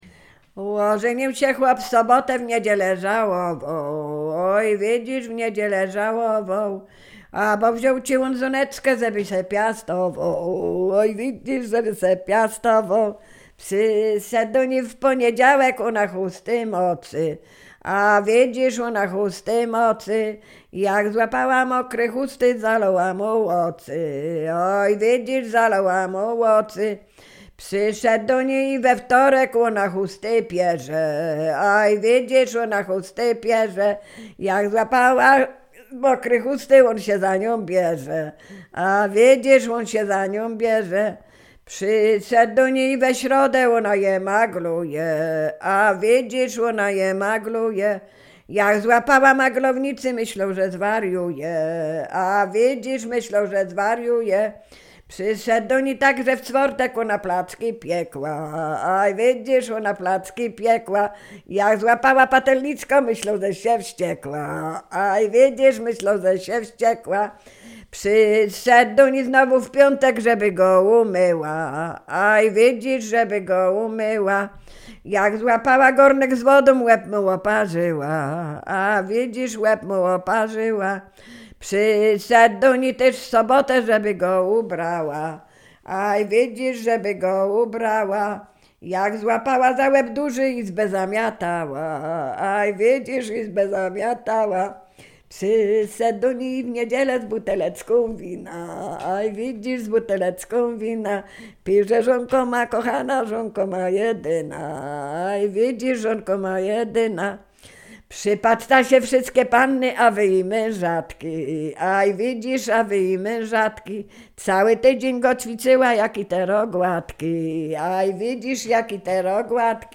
Sieradzkie
wojewódzctwo łódzkie, powiat sieradzki, gmina Brzeźnio, wieś Kliczków Mały
liryczne miłosne żartobliwe pieśni piękne